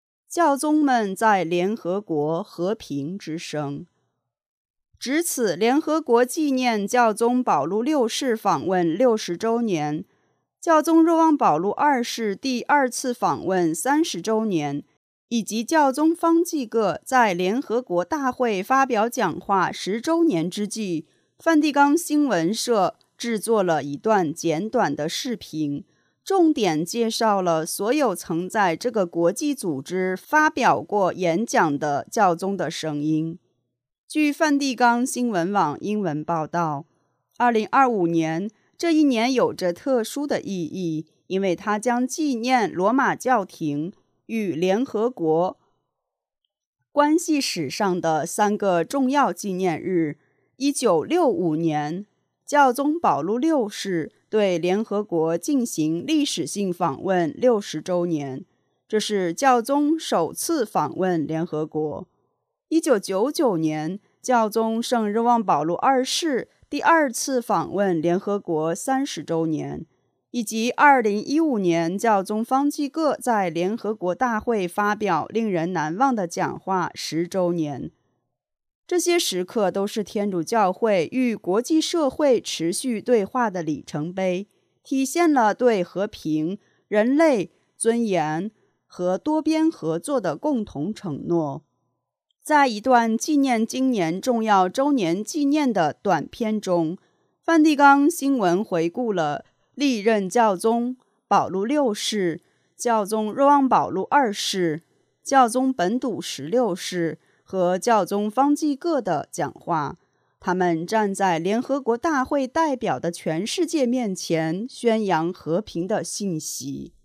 值此联合国纪念教宗圣保禄六世访问60周年、教宗圣若望保禄二世第二次访问30周年以及教宗方济各在联合国大会发表讲话10周年之际，梵蒂冈新闻社制作了一段简短的视频，重点介绍了所有曾在这个国际组织发表过演讲的教宗的声音。
在一段纪念今年重要周年纪念的短片中，梵蒂冈新闻回顾了历任教宗——保罗六世、教宗圣若望保禄二世、教宗本笃十六世和教宗方济各——的讲话，他们站在联合国大会代表的全世界面前，宣扬和平的信息。